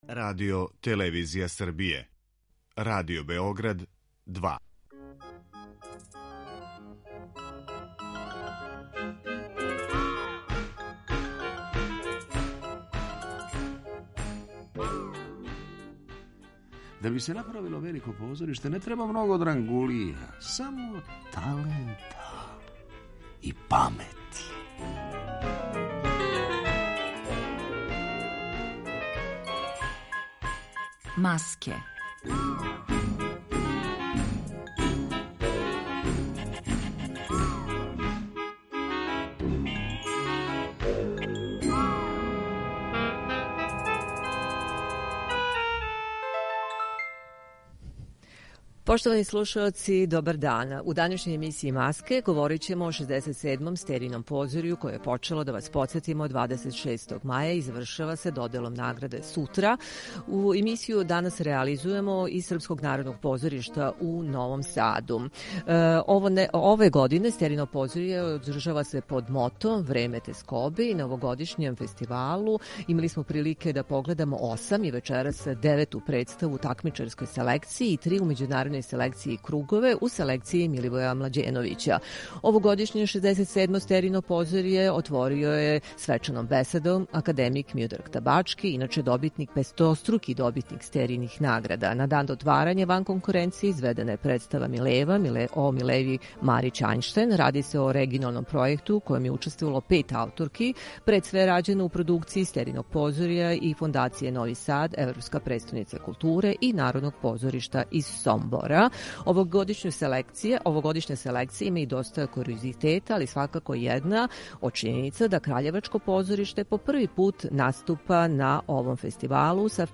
У данашњој емисији Маске говорићемо о 67. Стеријином позорју, које је почело 26. маја и завршиће се доделом награда 3. јуна 2022. године. Емисија ће бити реализована из Српског народног позоришта у Новом Саду.